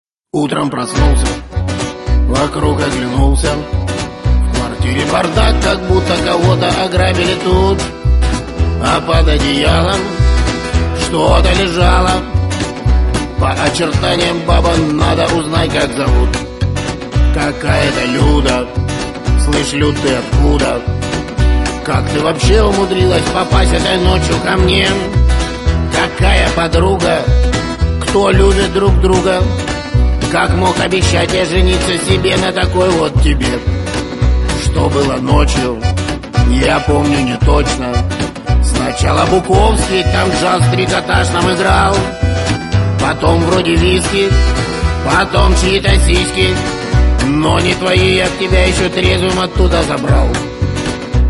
гитара
веселые
под гитару